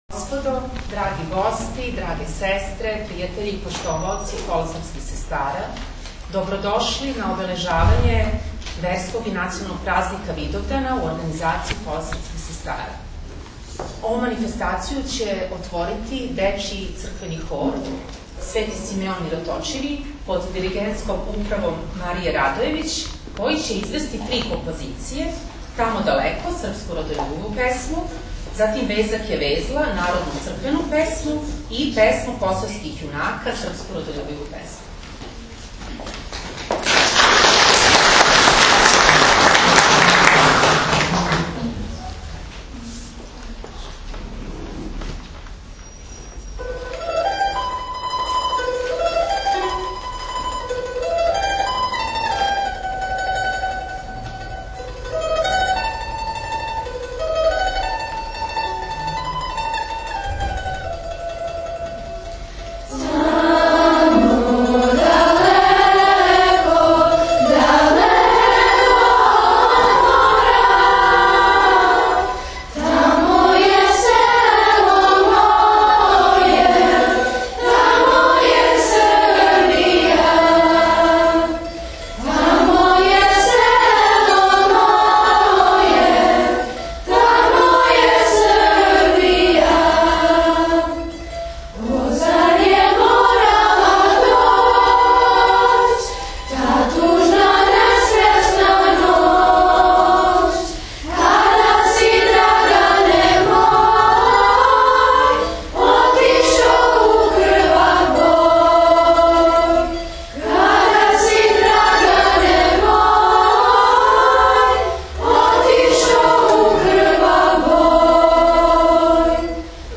Чланице Кола српских сестара приредиле су синоћ Видовданско вече на којем су награђени ученици са конкурса Снага доброте.